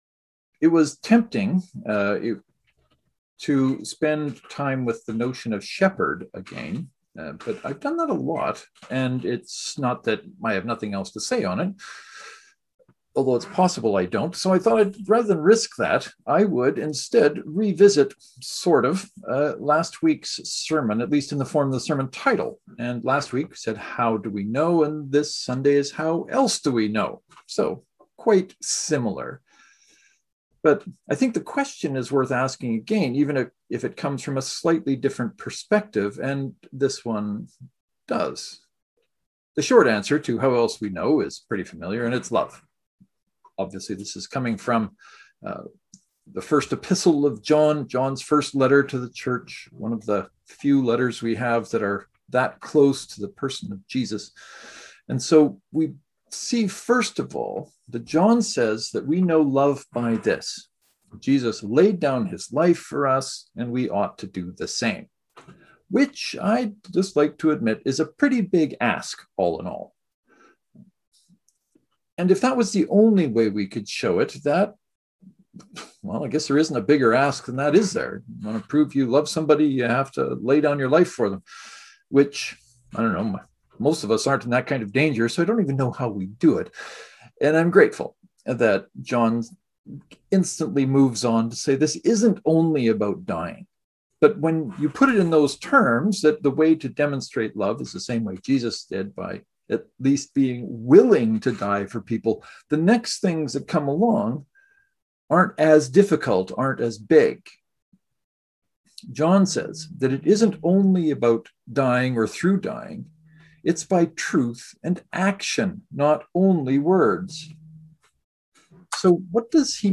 The sermon covers several things, including how to let the Holy Spirit help us choose what it is we should do in the actual moment we have.